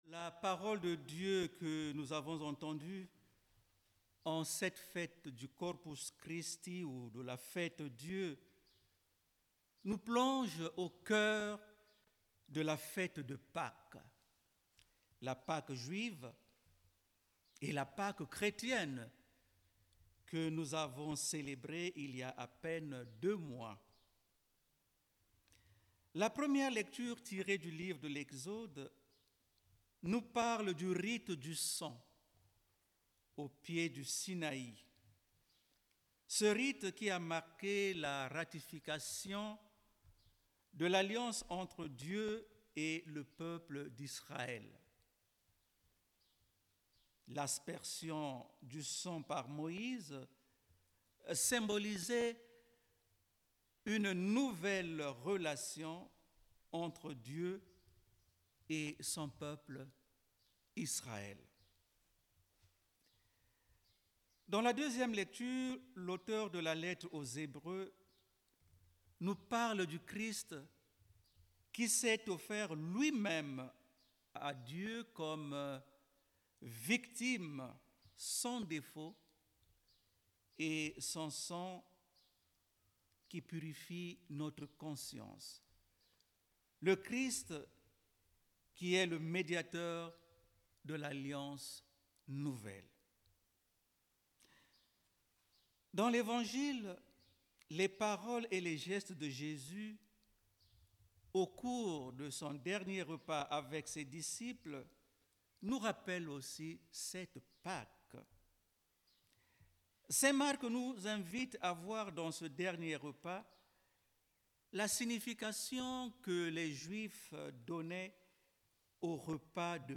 Grâce à un enregistrement en direct , ceux qui le souhaitent peuvent écouter sa réflexion.